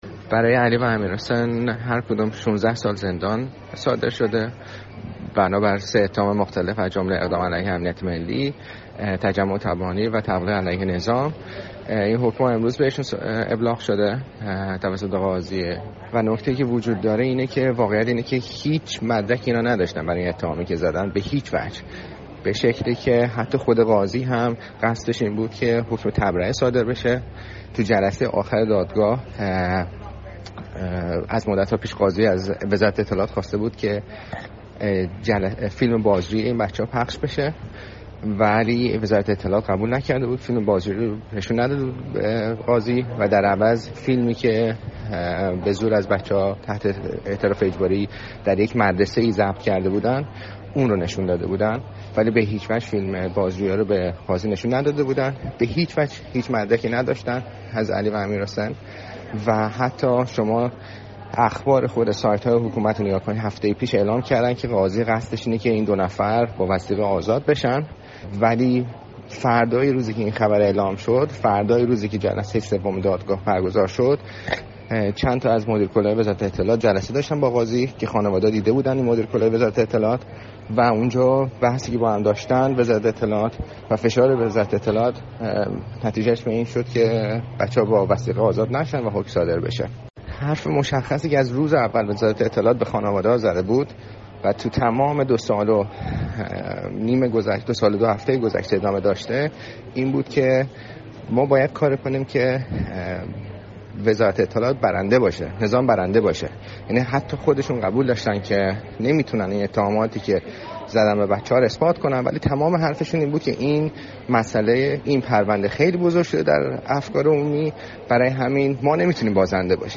گفت‌وگو با رادیو فردا